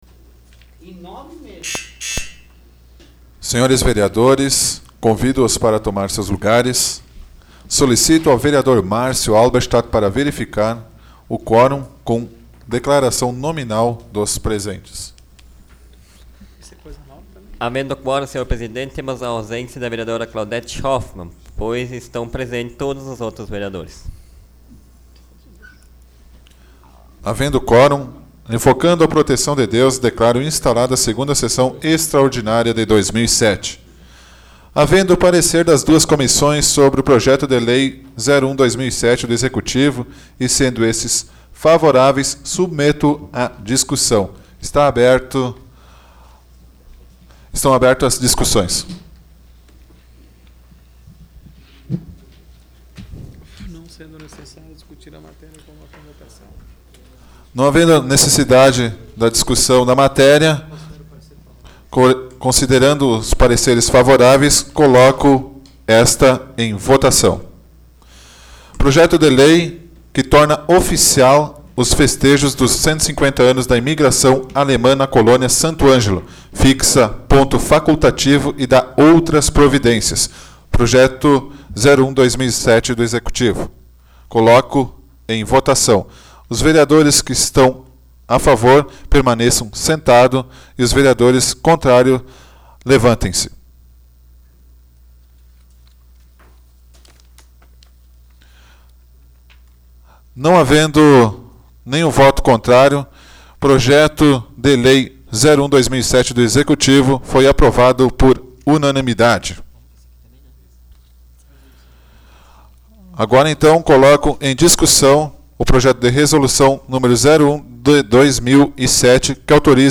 Áudio da 31ª Sessão Plenária Extraordinária da 12ª Legislatura, de 08 de janeiro de 2007